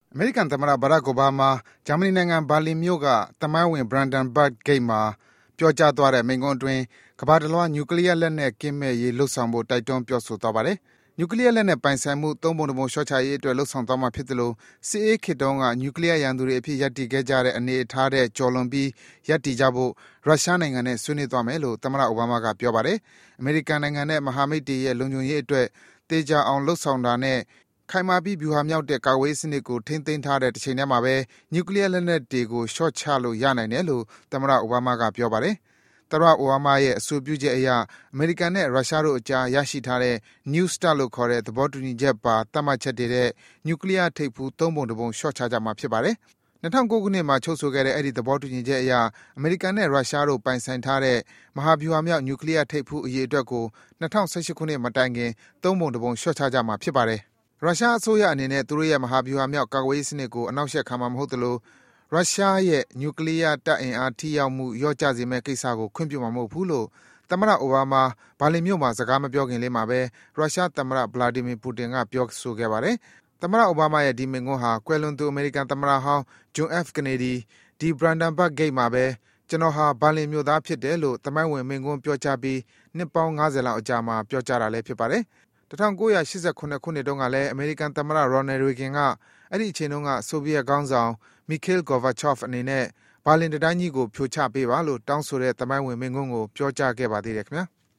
အိုဘာမား ဘာလင်မိန့်ခွန်း